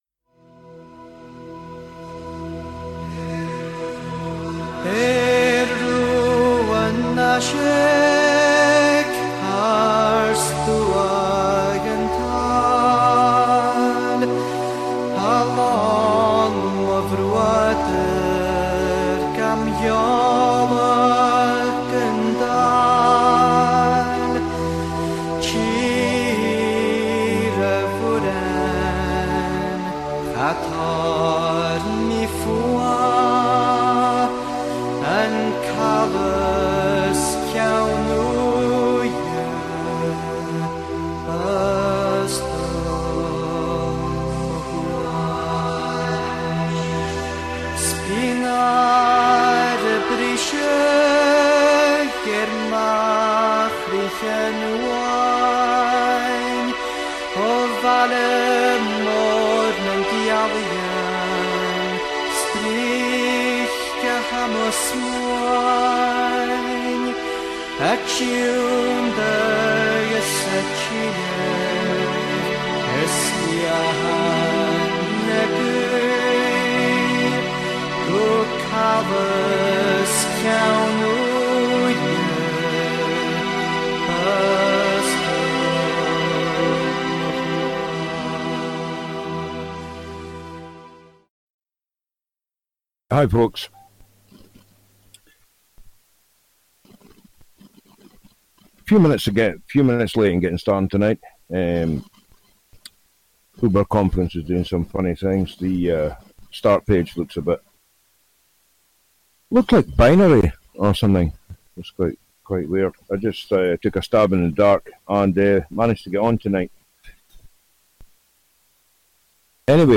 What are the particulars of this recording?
Callers are welcome to contribute. This weekly radio show broadcasts live every Thursday from Inverness, Scotland, transmitting real, uncensored and unsanitized philosophy, news and perspectives.